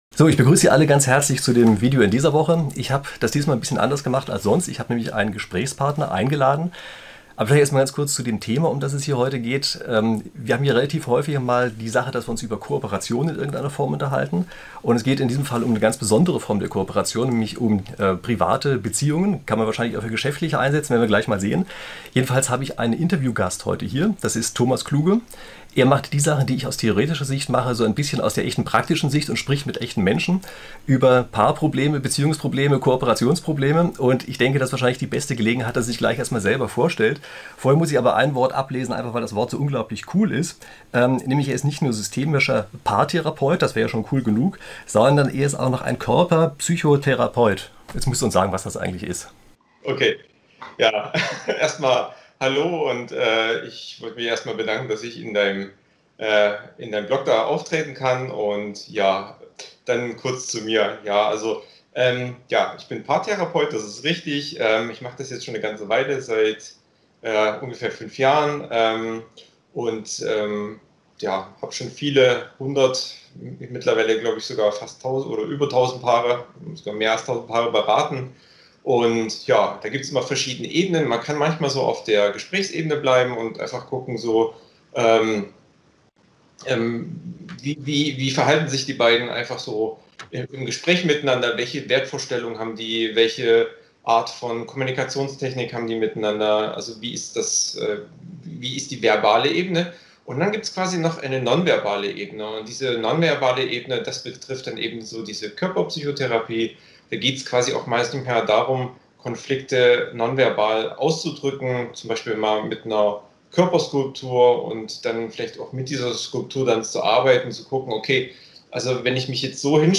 In dem Interview sprechen wir fünf Bereiche an: Gleichheit vs. Gerechtigkeit Streit Was wünschen sich Paare? Können Dritte helfen?